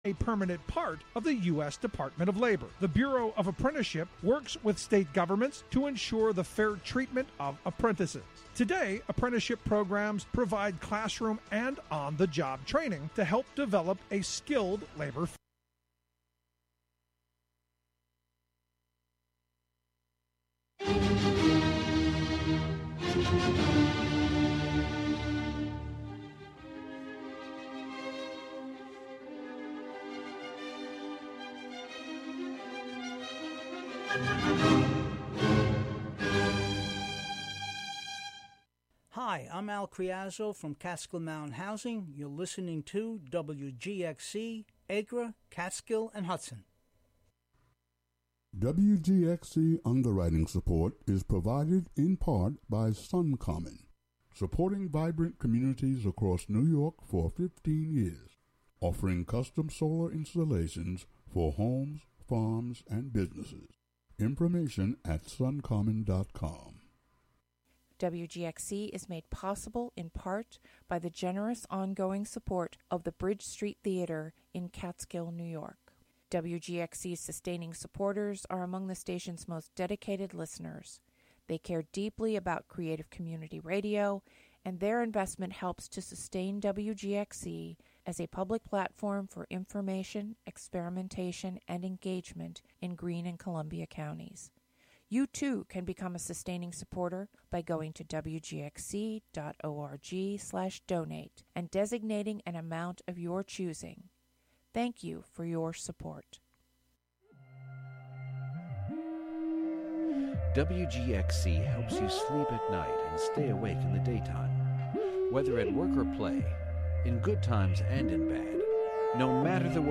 8pm This broadcast continues the Race and Wellness ser... This broadcast continues the Race and Wellness series with "Food for the Soul."